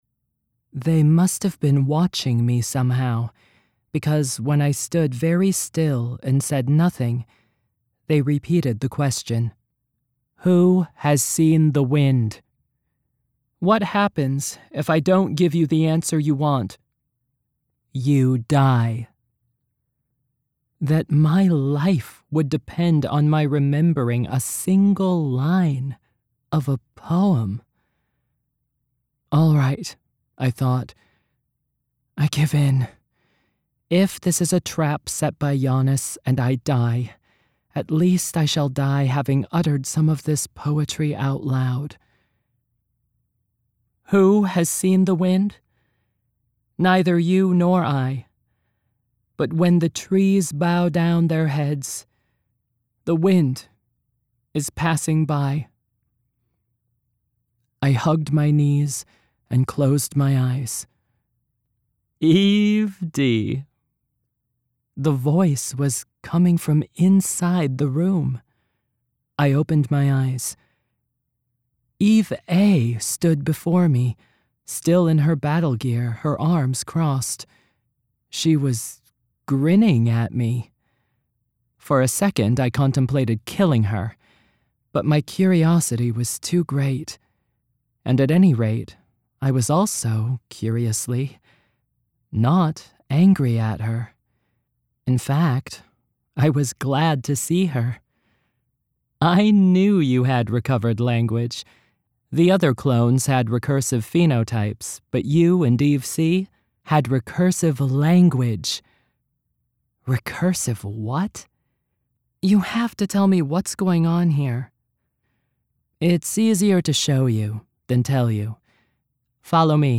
Narration Samples
1st Person
Two women